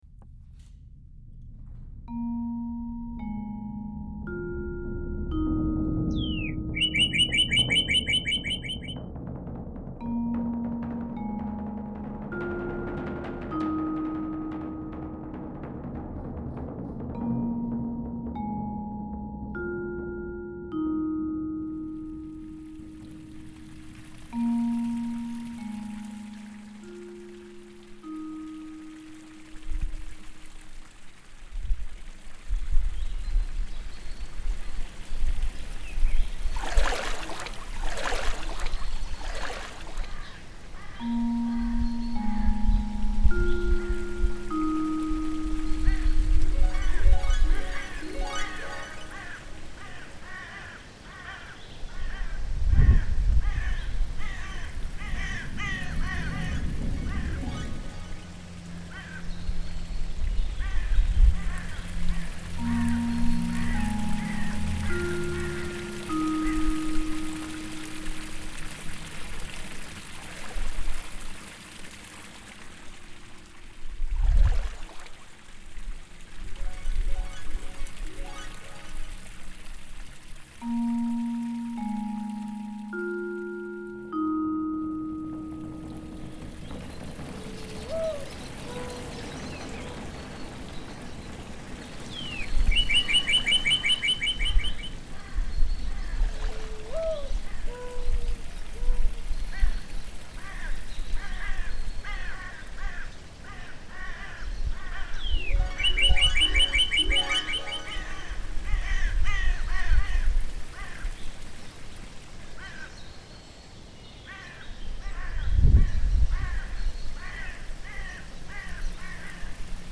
The sounds used in this soundscape are very nature-centered to fit the landscape painting.
I decided to bring this sound into the soundscape with the images of animals pushing through it.
Most of the sounds are original, though there are some royalty free sounds, such as the bird chirps in the opening and the harp. Gathering some of the nature sounds such as the birds and the splashing sounds were difficult but worth it when listening to the finished product.
Tell me, what stories come to your mind as you hear the harp, the gushing water, the birds chirping loudly?